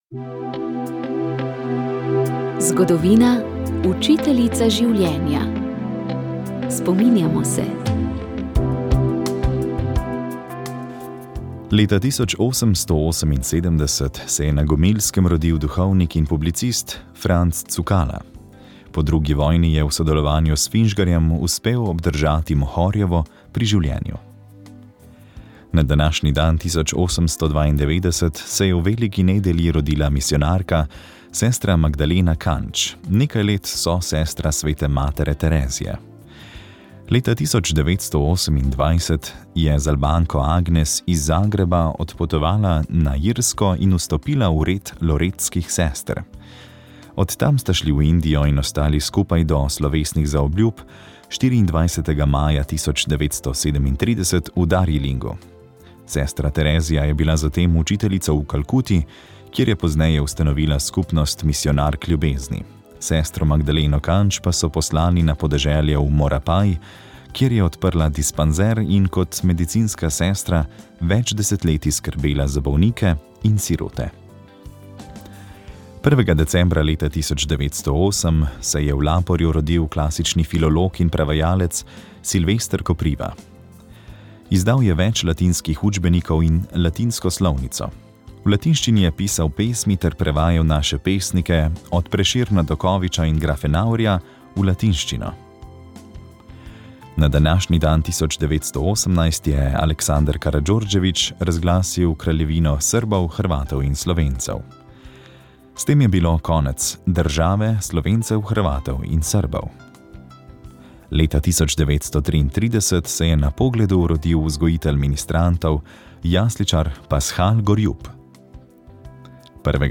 V Sobotnem duhovnem večeru ste najprej slišali odlomke Božje besede, berili in evangelij, ki so izbrani za 22. nedeljo med letom. Sledil je duhovni nagovor, ki ga je pripravil škof Jurij Bizjak in molitev rožnega venca, s katerim smo prosili za blagoslov novega šolskega in veroučnega leta. Mariji v čast smo zapeli lavretanske litanije in se z molitvijo izročili v njeno varstvo.